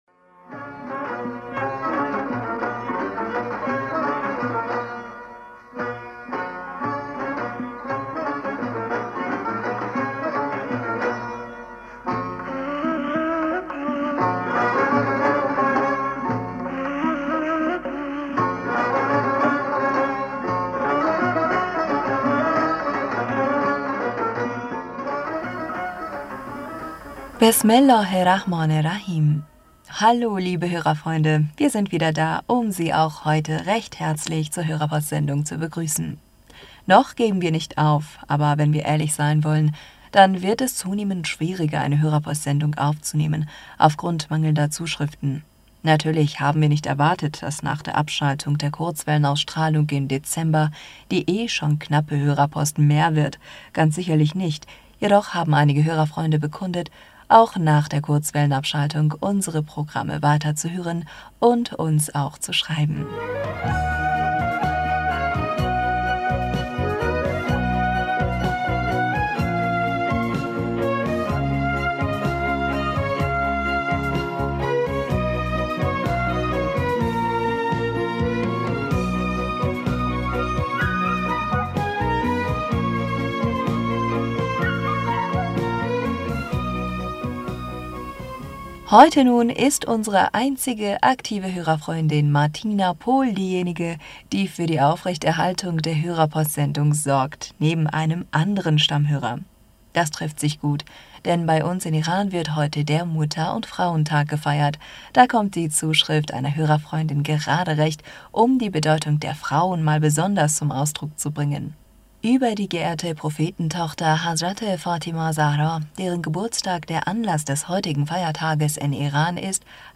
Hörerpostsendung am 23. Januar 2022 Bismillaher rahmaner rahim - Hallo liebe Hörerfreunde, wir sind wieder da um Sie auch heute recht herzlich zur Hörerpo...